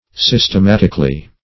Systematically \Sys`tem*at"ic*al*ly\, adv.